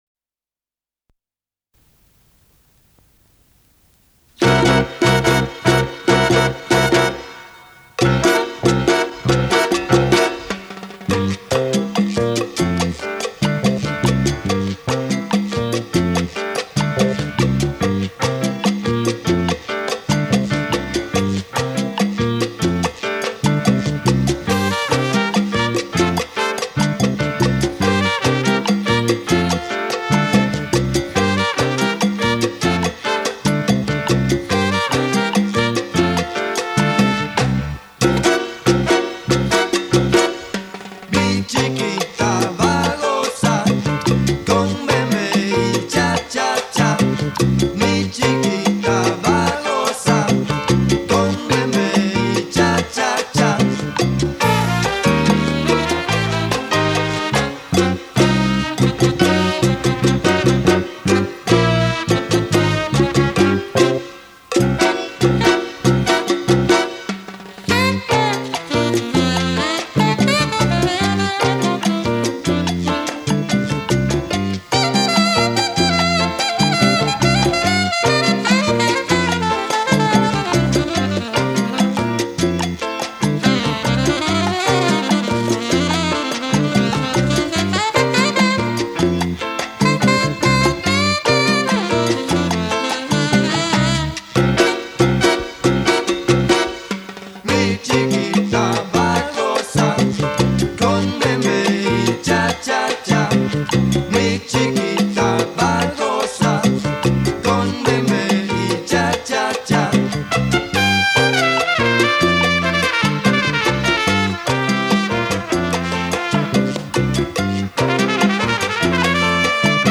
SALSA